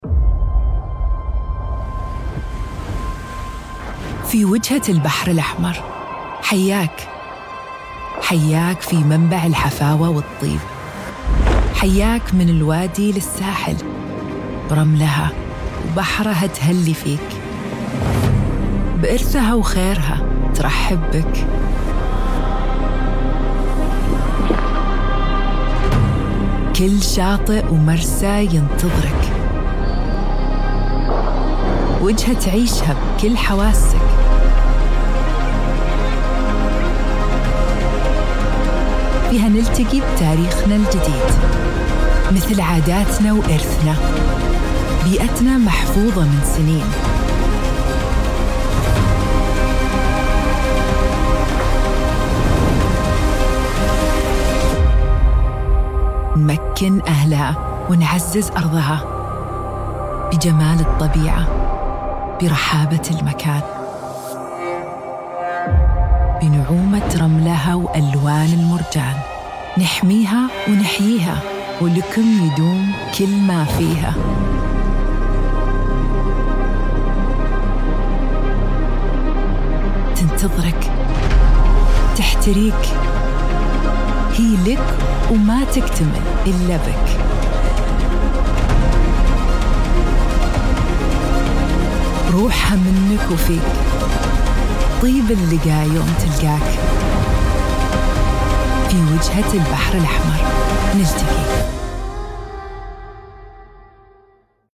Tief, Natürlich, Unverwechselbar
Kommerziell